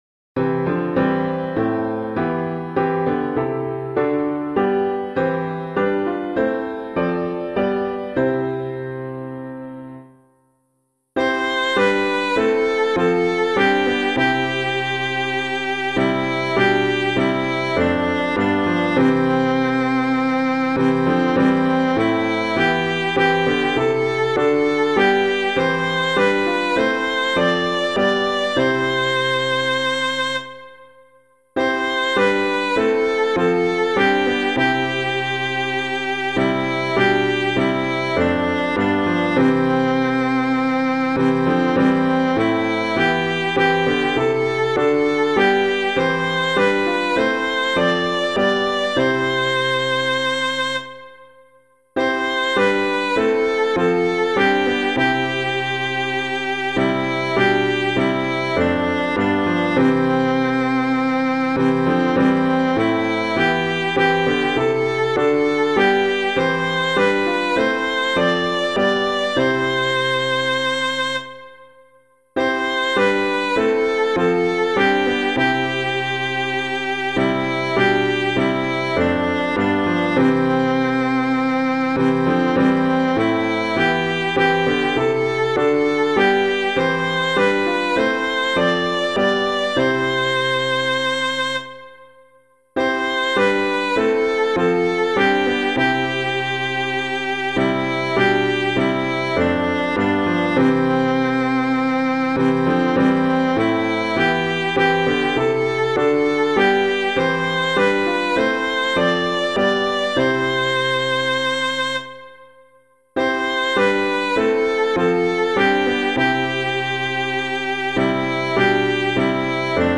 Hymn suitable for Catholic liturgy
Within the Father's House [Woodford - OPTATUS VOTIS OMNIUM] - piano.mp3